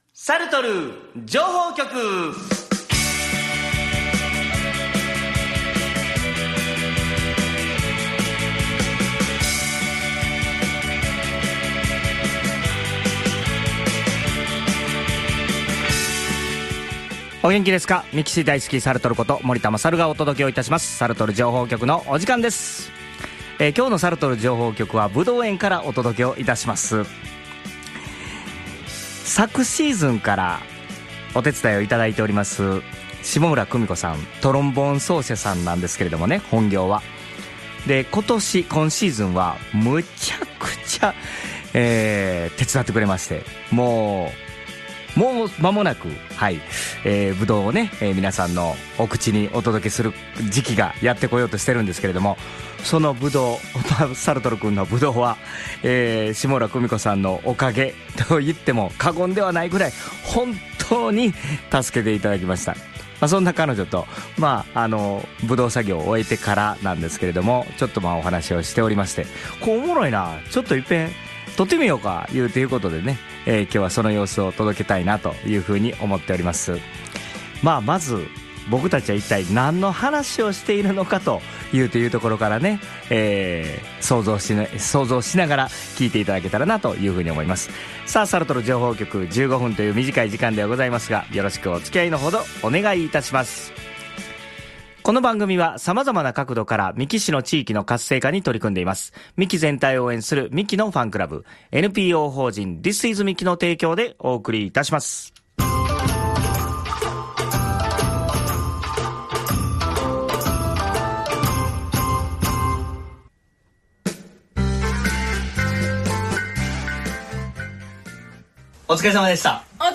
で、今回は【旬でっせ〜】 2人で共通の話題といえばぶどうだし、一回目やからぶどうの話をしようかと2人とも張り切って録りました。